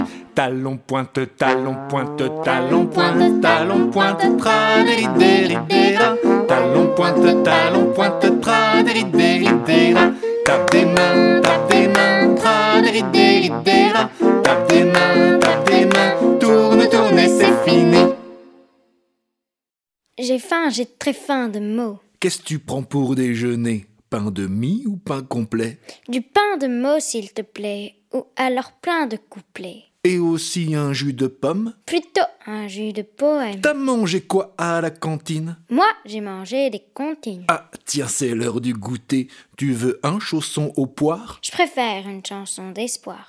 15/09/2021 Au cours d’une déambulation immersive à la rencontre de délicieux sons, on goûte aux exquises salades de bruits que les deux protagonistes nous concoctent, ainsi qu’aux jeux de mots croquants auxquels ils se livrent. Une plongée dans un océan de douceurs, qui regorge d’une série de « sonniers » (selon le principe de l’imagier, mais en sons), mais aussi de quelques devinettes sonores savoureuses et autres petits jeux d’écoute de chants d’oiseaux qu’on apprend à reconnaître. Deux voix qui se rencontrent, celle d’un adulte et celle d’une enfant, pour livrer une promenade envoûtante, qui sonne comme une invitation à l’émerveillement.